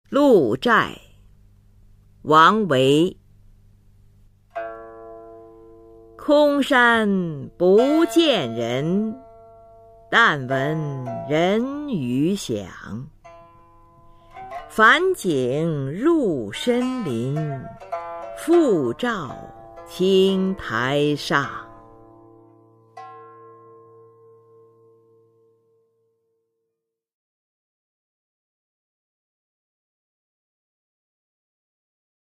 [隋唐诗词诵读]王维-鹿柴 配乐诗朗诵